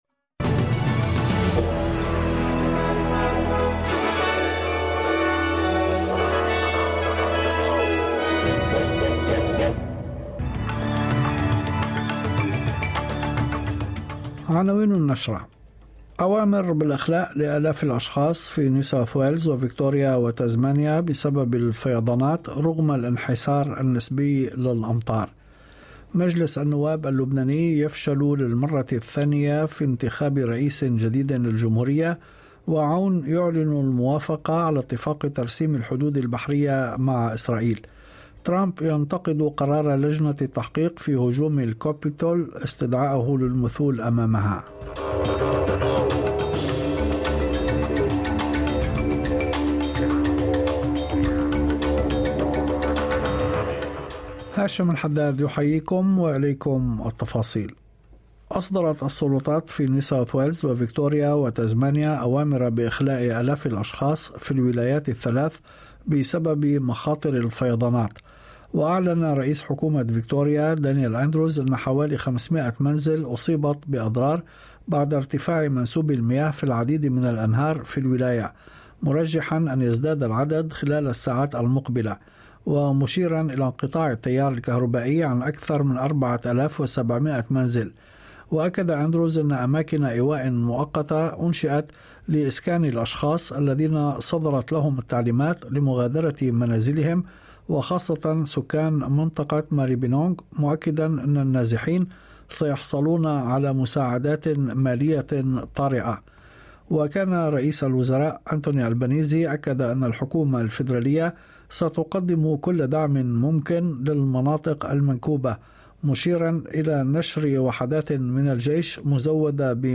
نشرة أخبار المساء 14/10/2022